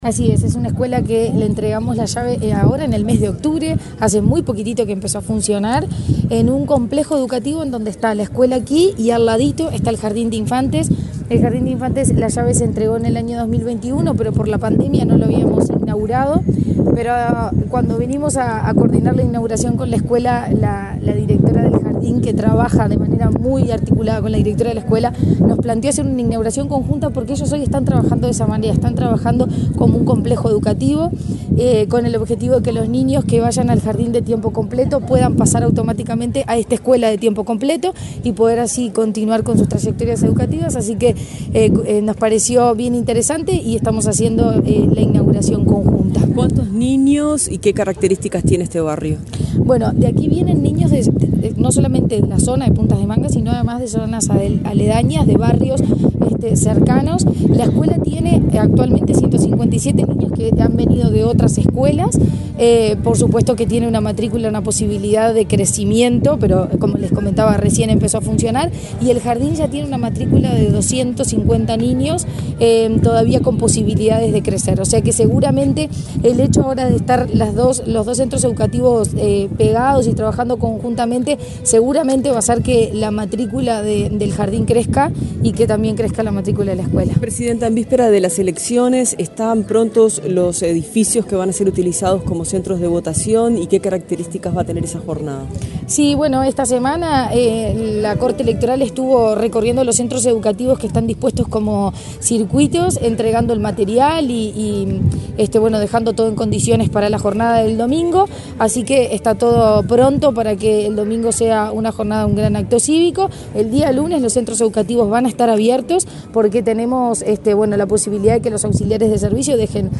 Declaraciones de la presidenta de ANEP, Virginia Cáceres
Declaraciones de la presidenta de ANEP, Virginia Cáceres 25/10/2024 Compartir Facebook X Copiar enlace WhatsApp LinkedIn Este viernes 25, la presidenta de la Administración Nacional de Educación Pública (ANEP), Virginia Cáceres, dialogó con la prensa, luego de participar en la inauguración de la escuela n.° 410, de tiempo completo, y el jardín de infantes n.° 401 de Puntas de Manga, en Montevideo.